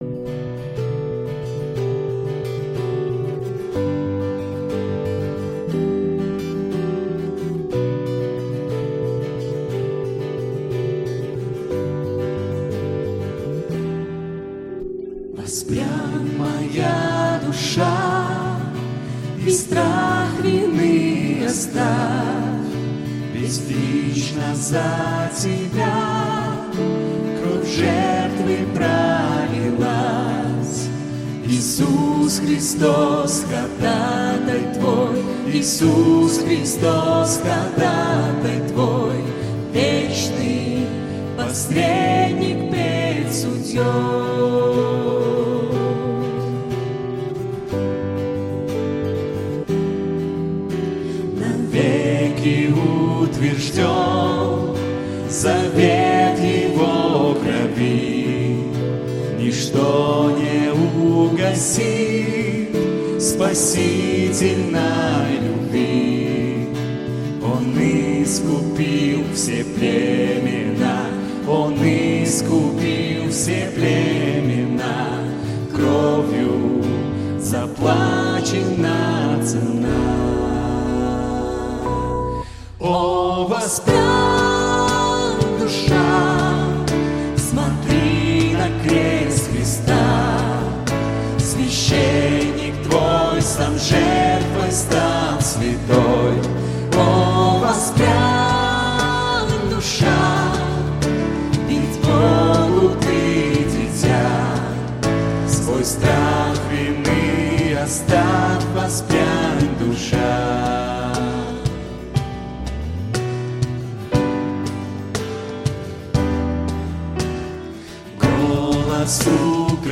||: Cm | Cm | Ab | Fm :||